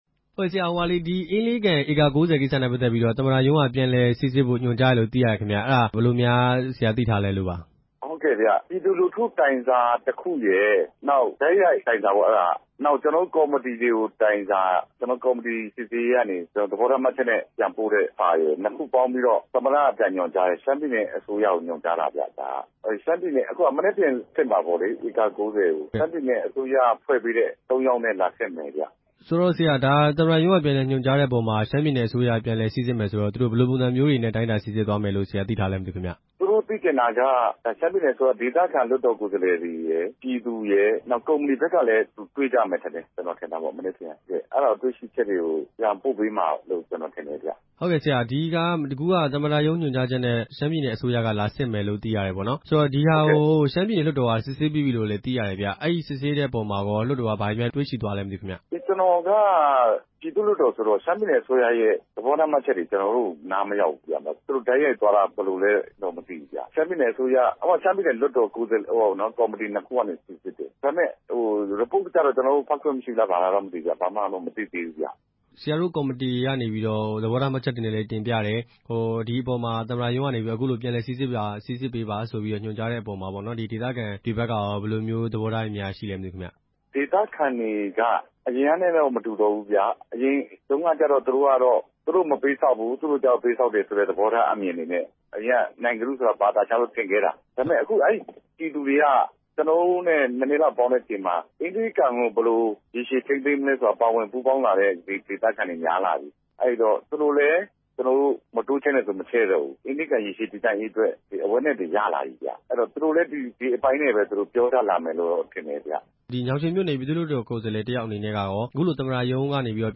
အင်းလေးဒေသက မြေဧက ၉ဝ လုပ်ပိုင်ခွင့်ကိစ္စ မေးမြန်းချက်